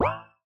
cancel.opus